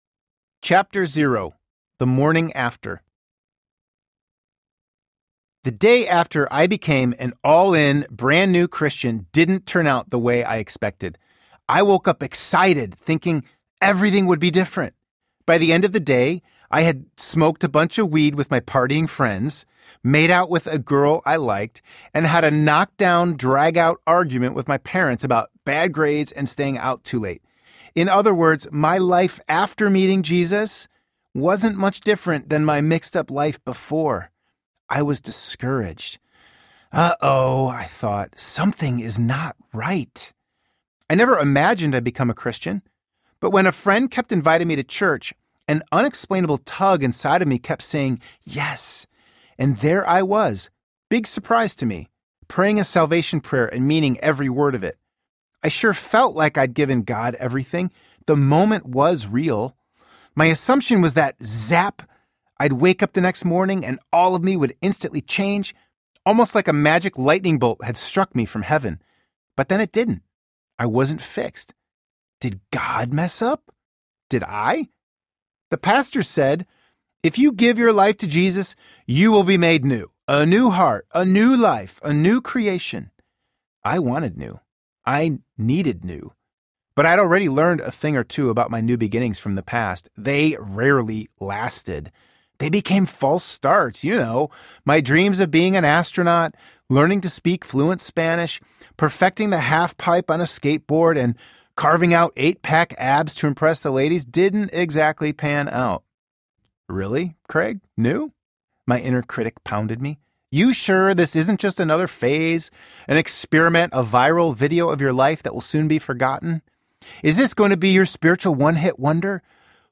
How to Follow Jesus Audiobook
6.0 Hrs. – Unabridged